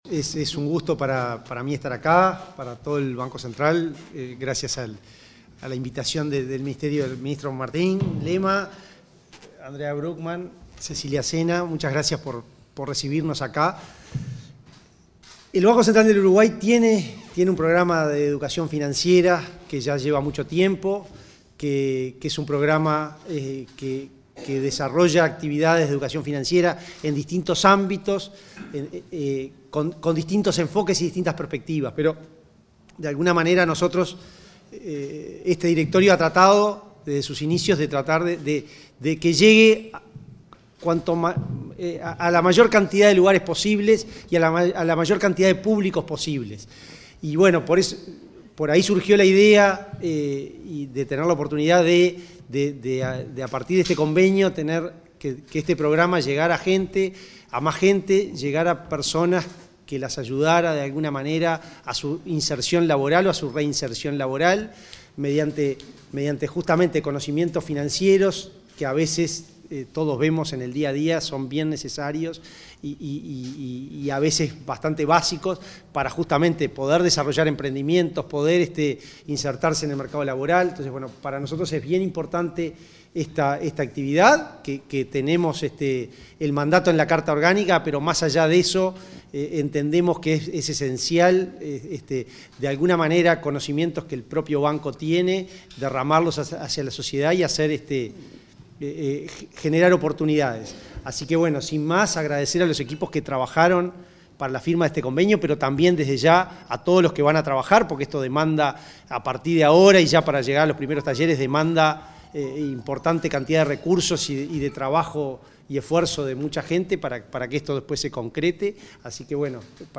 Palabras de autoridades en convenio entre Mides y BCU